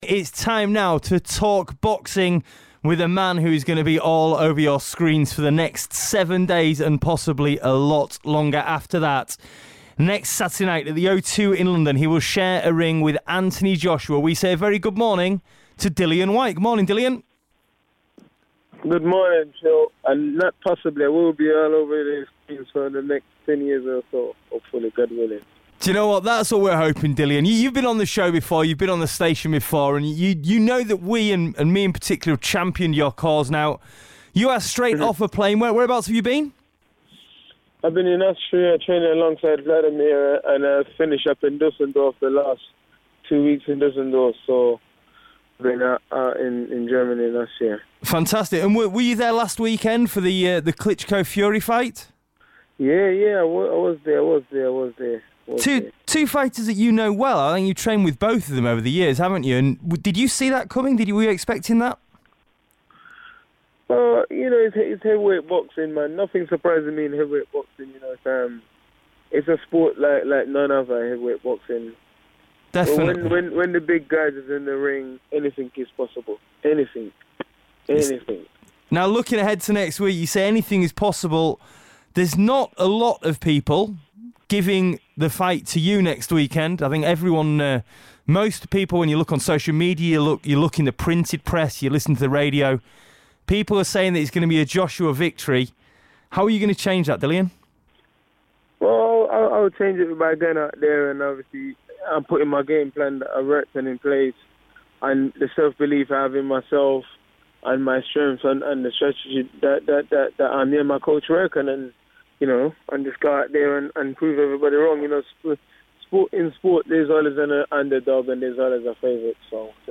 chats to boxer Dillian Whyte ahead of his fight against Anthony Joshua